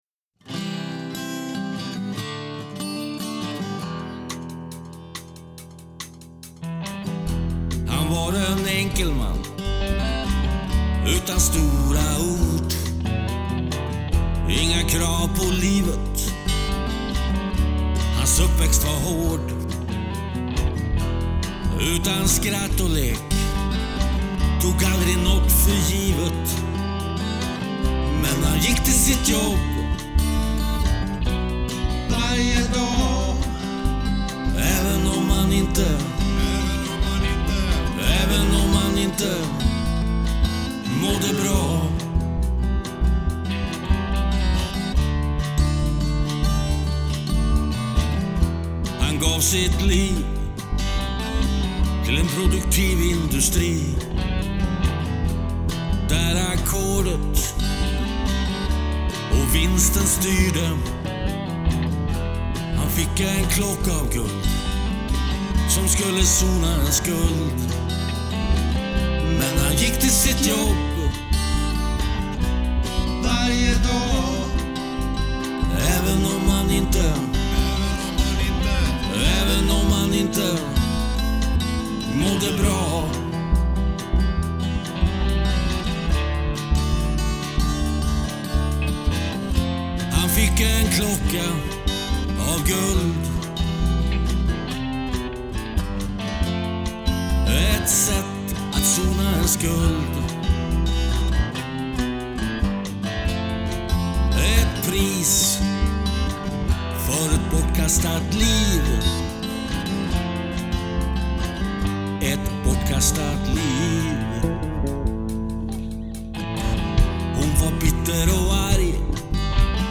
Bandet spelar svensk rock och pop med stora influenser från ”proggen”.
Fyra olika musikstilar möts och förenar sig.
Trummor
Gitarr/sång
Sång o Gitarr